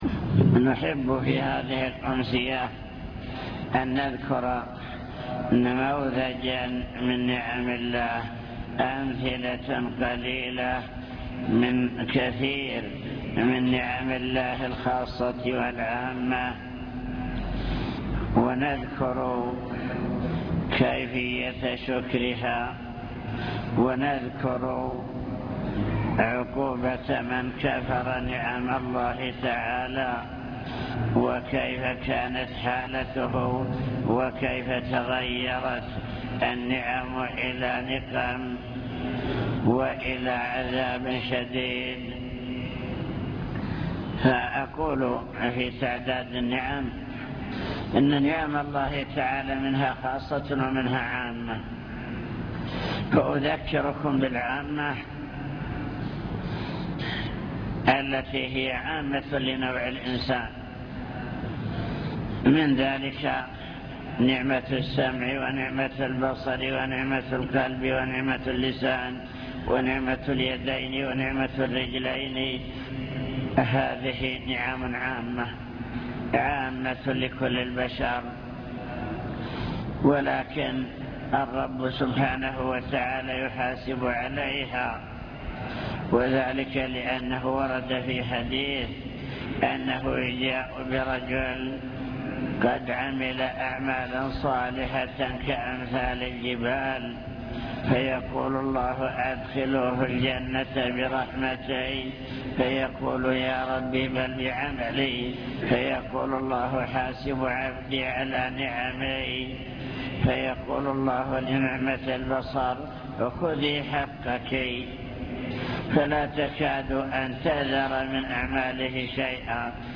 المكتبة الصوتية  تسجيلات - محاضرات ودروس  محاضرة بعنوان شكر النعم (1) ذكر نماذج لنعم الله تعالى العامة وكيفية شكرها